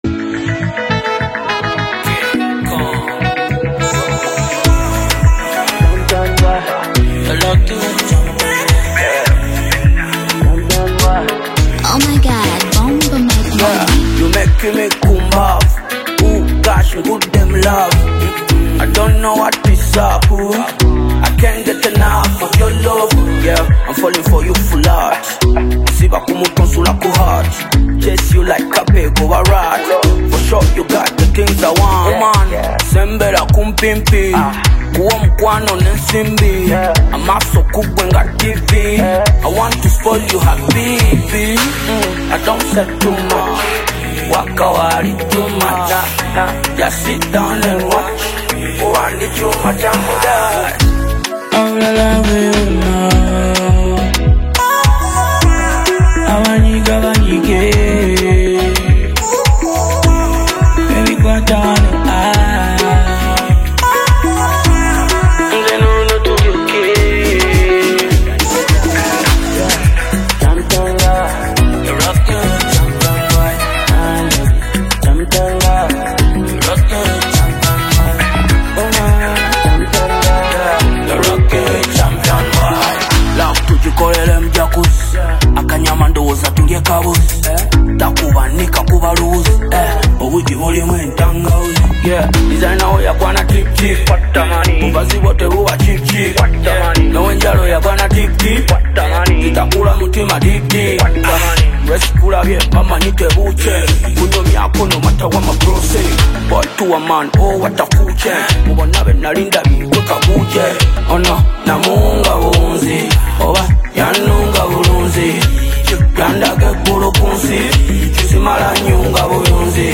Afrobeats/Hip-Hop single